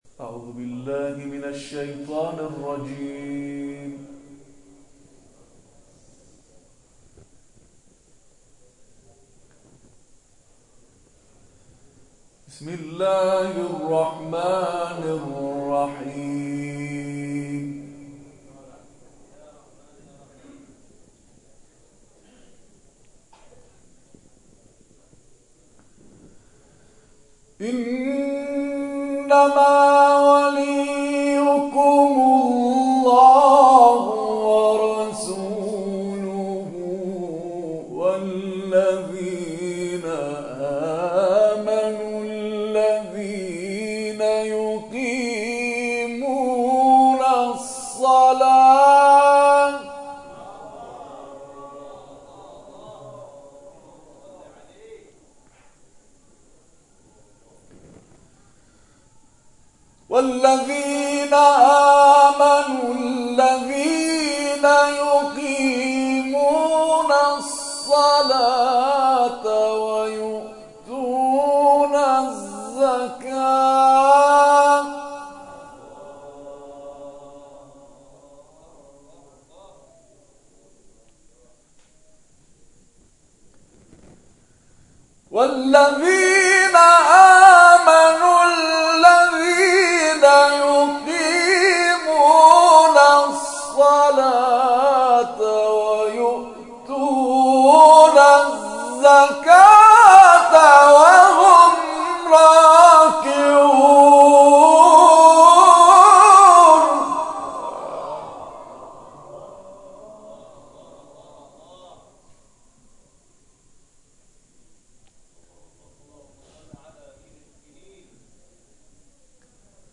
گروه جلسات و محافل: همزمان با 13 رجب سالروز میلاد امیر‌المؤمنان علی(ع) مراسم جشنی به همین منظور به همت مؤسسه احسن‌الحدیث با حضور اساتید و قاریان ممتاز و بین‌المللی برگزار شد.